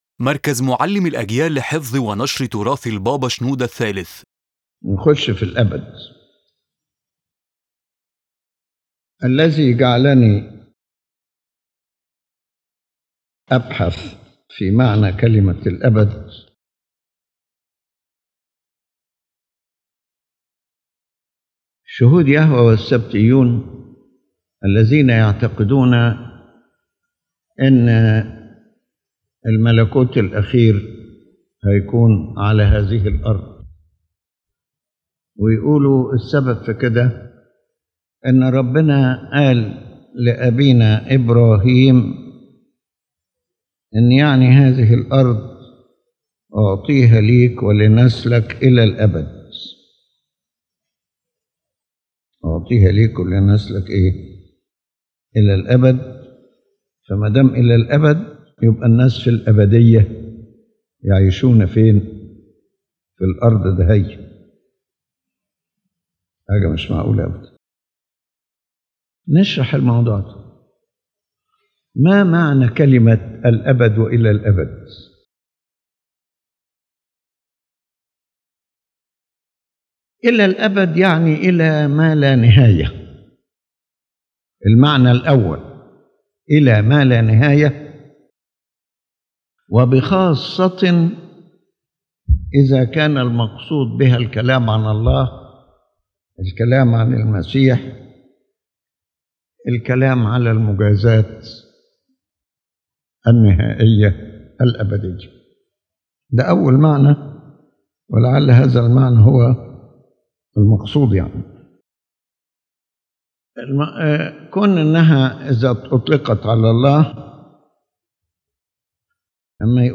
His Holiness Pope Shenouda III addresses in this lecture the meaning of the word “Eternity” and “to eternity” as used in the Holy Bible, explaining that the word has more than one meaning depending on the context and intent: sometimes it denotes God’s infinity and His attributes, and sometimes it is used to indicate a long and limited duration within human life or history.